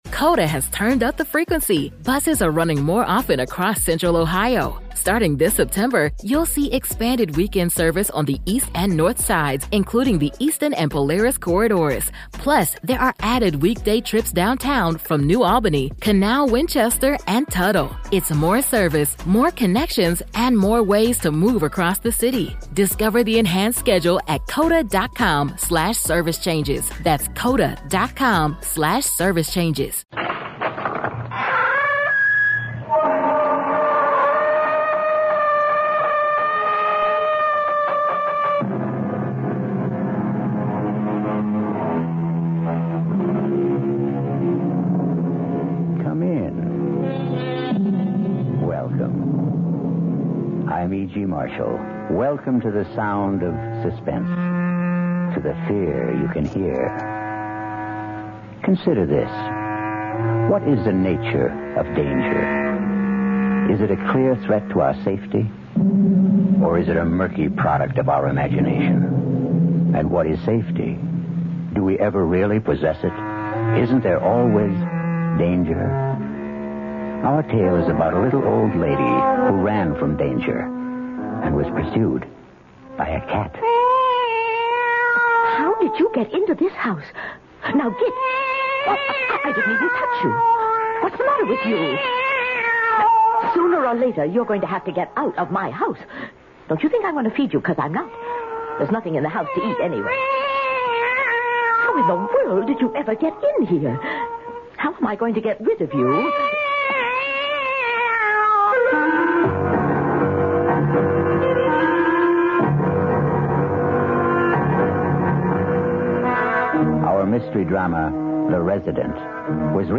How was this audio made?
On this episode of the Old Time Radiocast we present you with two stories from the classic radio program CBS Radio Mystery Theater!